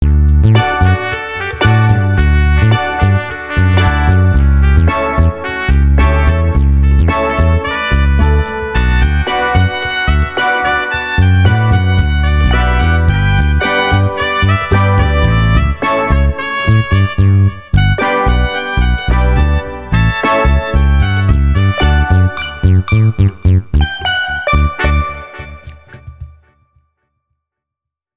Dikke_domme_regga.mp3